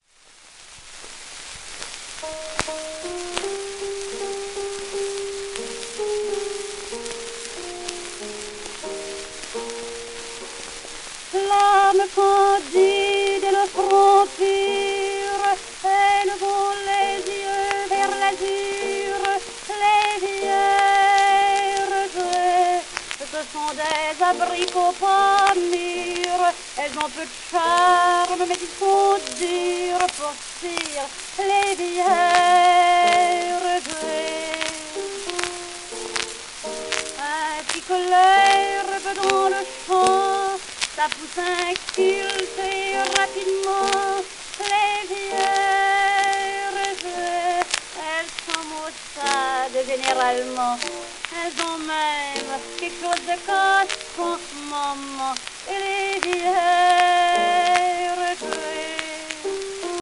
盤質B+, キズ, 面スレ  ※画像クリックで試聴音源が流れます